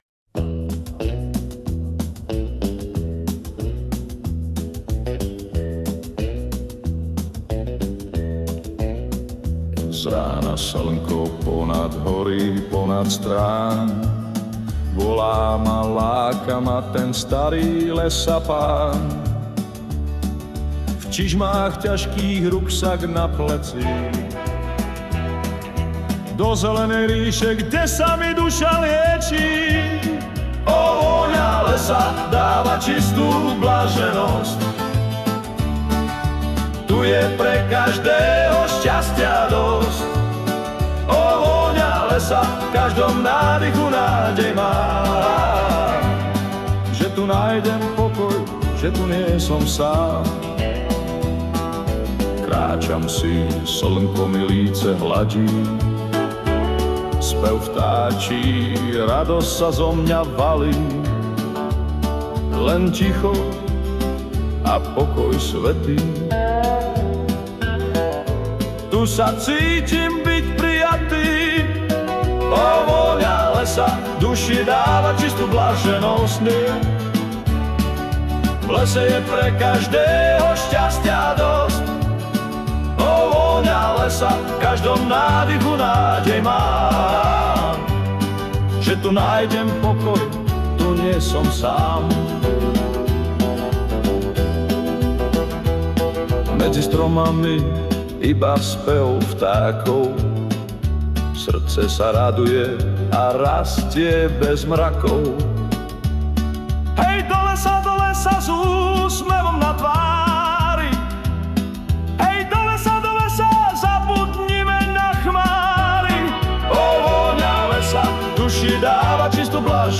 Hudba a spev AI